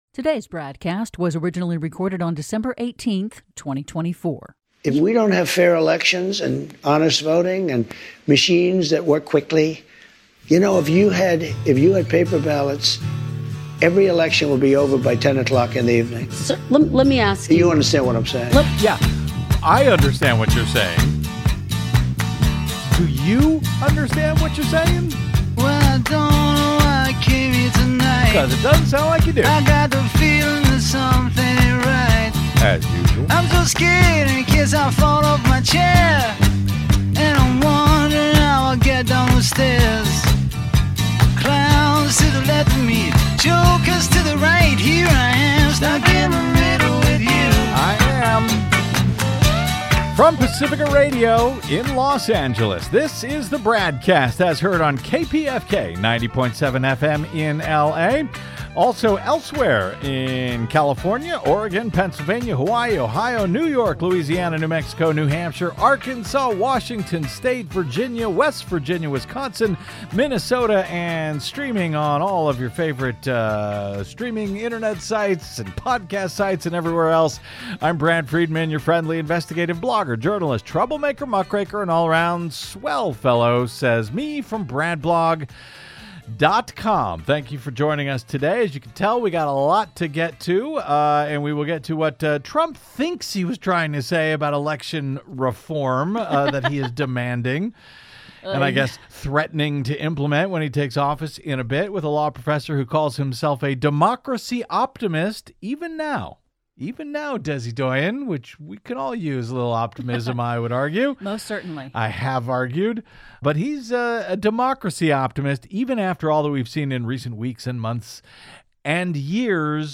Originating on Pacifica Radio's KPFK 90.7FM in Los Angeles and syndicated coast-to-coast and around the globe!